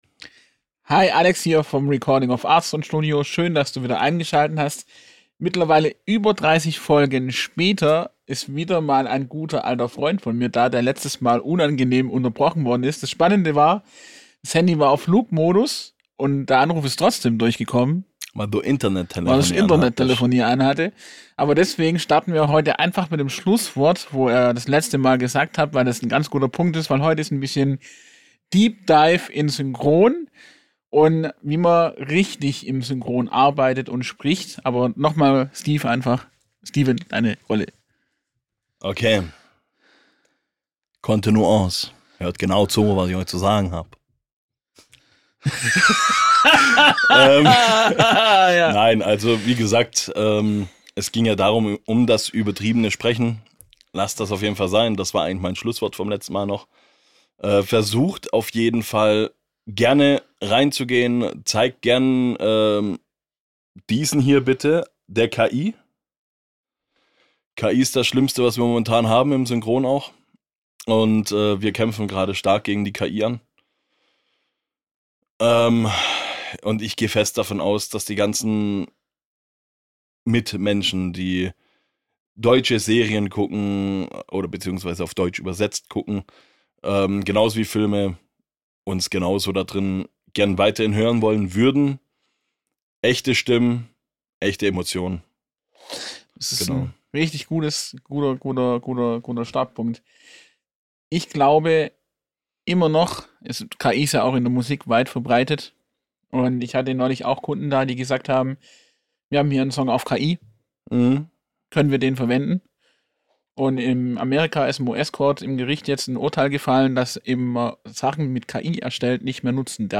Rapper und Synchronsprecher – und diesmal wird’s grundsätzlich.